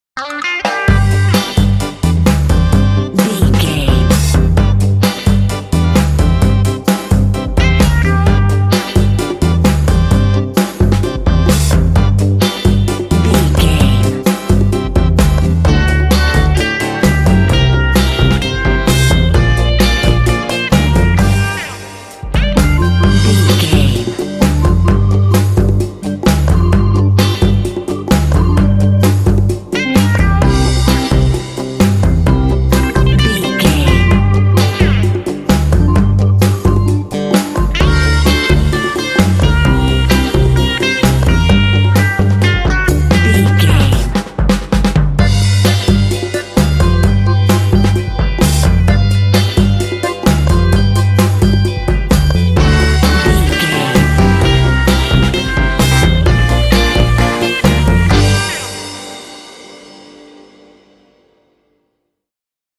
Uplifting
Aeolian/Minor
funky
smooth
groovy
driving
happy
bright
drums
brass
electric guitar
bass guitar
organ
percussion
conga
rock
Funk